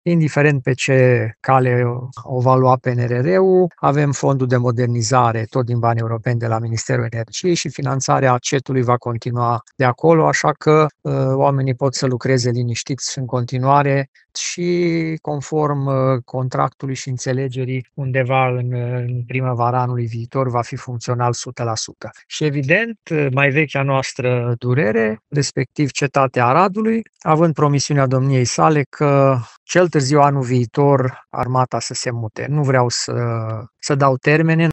Primarul Aradului, Călin Bibarţ, spune că sunt toate premisele ca în primăvară să fie gata noul CET şi tot anul viitor militarii ar urma să se mute din Cetate în fosta cazarmă din Gai.